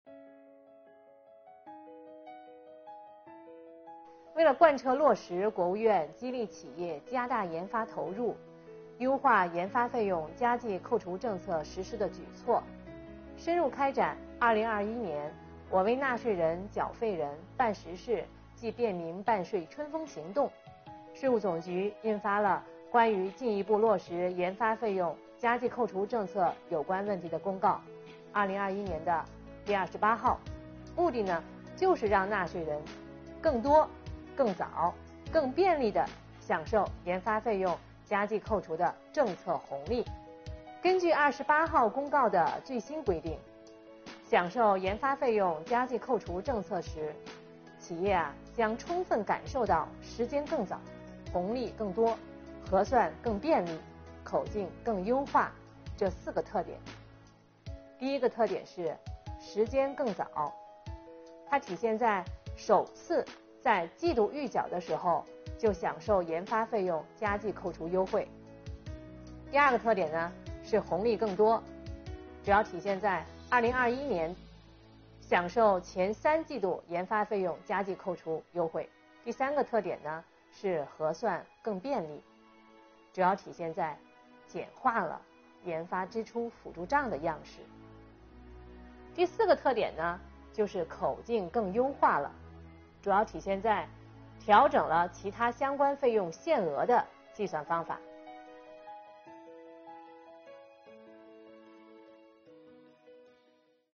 10月9日，国家税务总局推出最新一期“税务讲堂”网上公开课，税务总局所得税司副司长阳民详细解读研发费用加计扣除政策的有关背景、内容和办理方式等内容，帮助纳税人缴费人更好了解政策、适用政策。